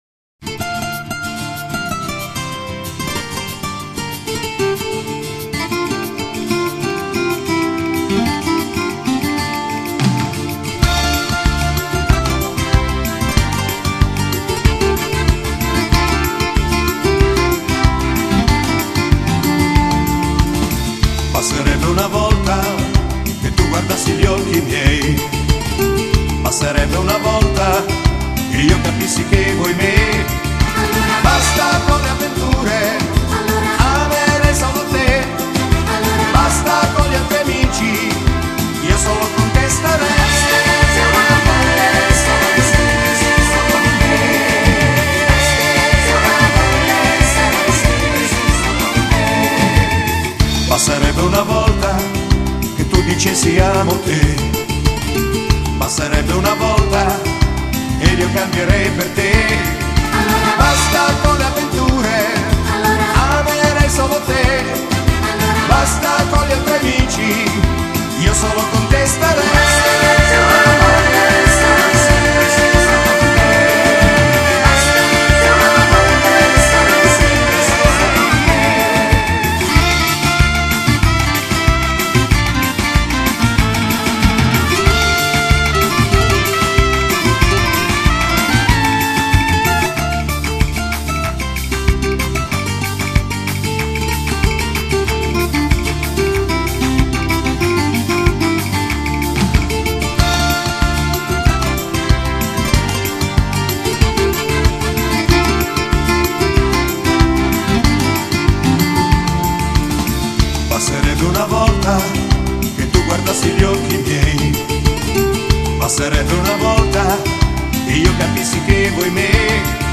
Genere: Flamenco